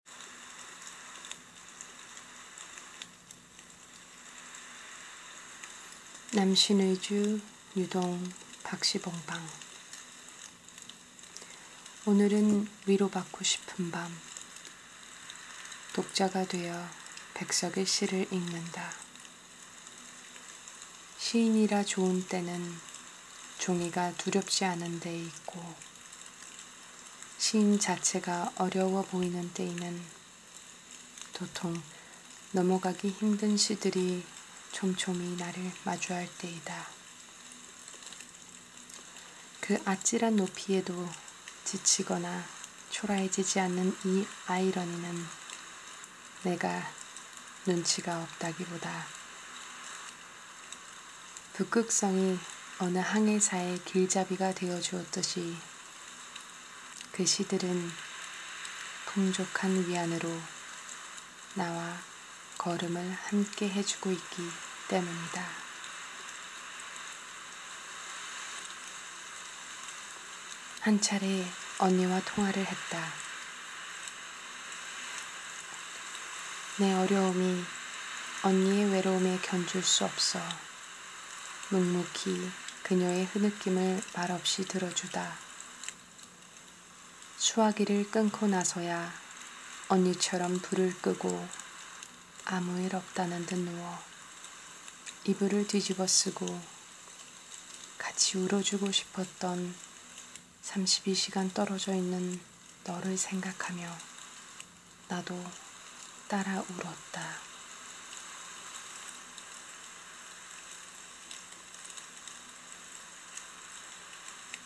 밑의 파일은 귀로 듣는 시입니다.